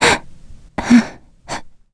Hilda-Vox_Sad_b.wav